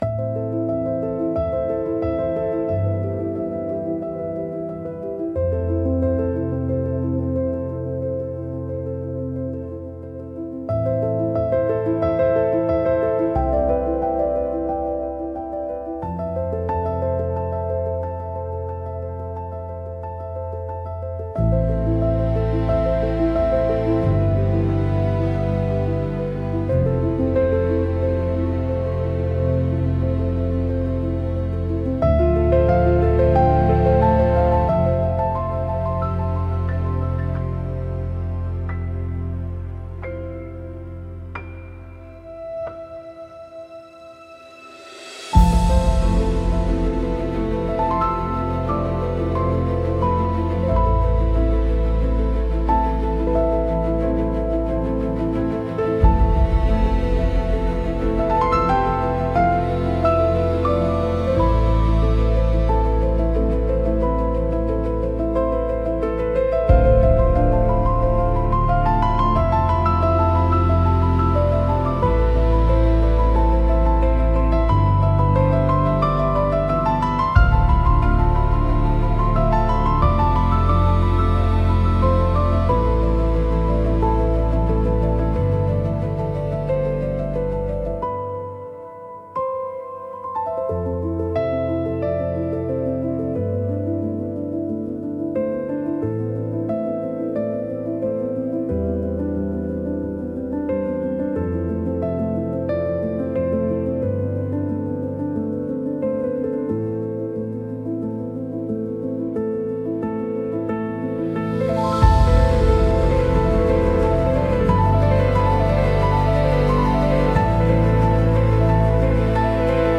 Sound Design-Audio Assets